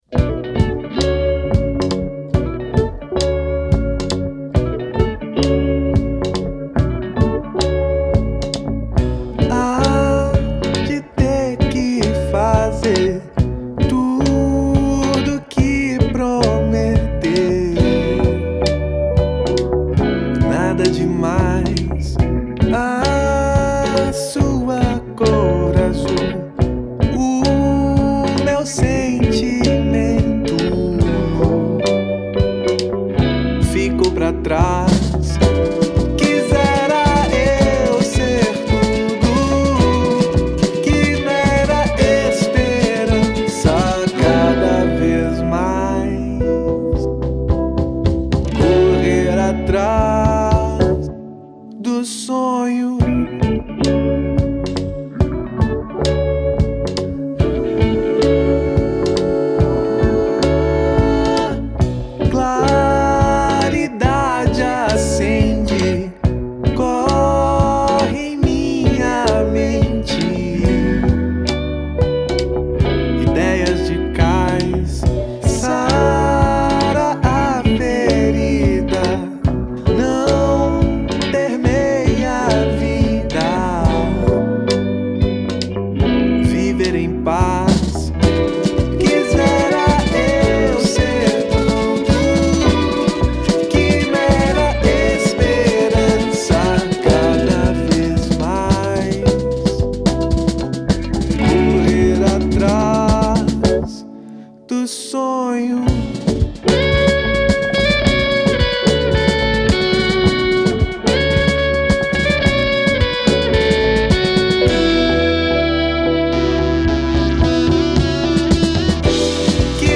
EstiloIndie